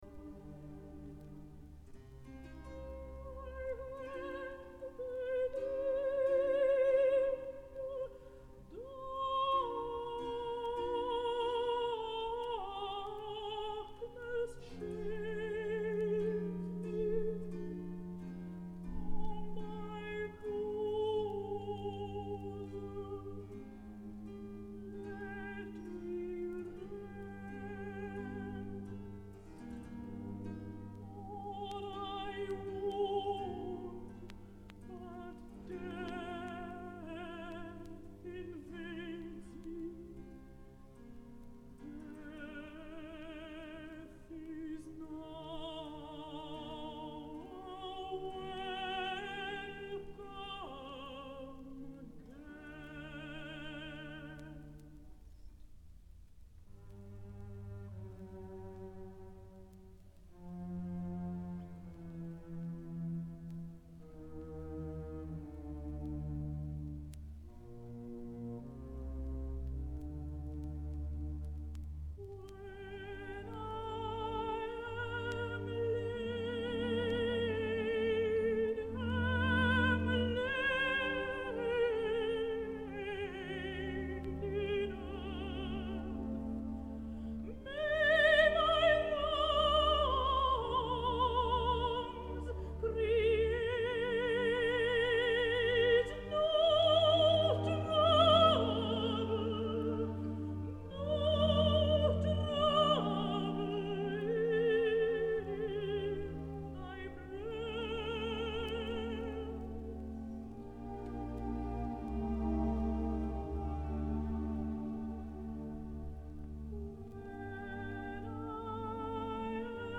Il évoque la mort imminente et malgré tout la pulsation semble éternelle. http